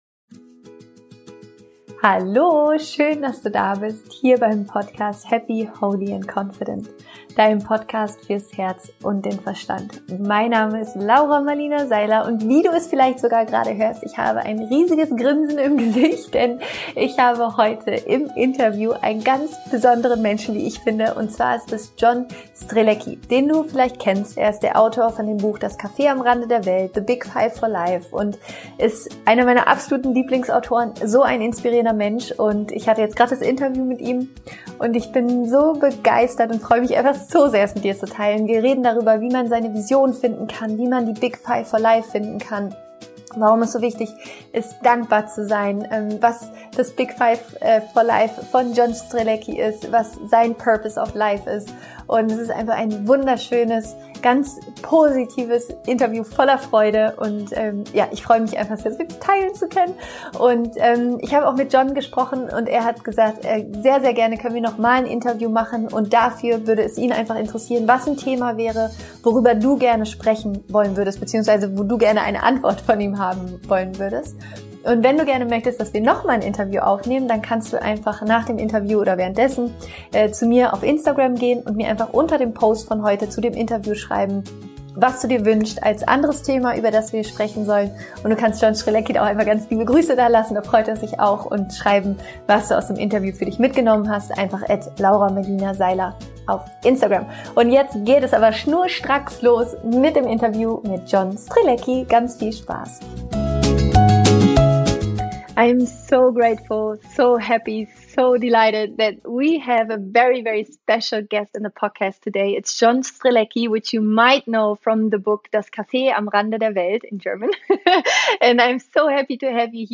Ich bin überglücklich heute einen meiner absoluten Lieblingsautoren und inspirierenden Menschen im Interview zu haben: John Strelecky.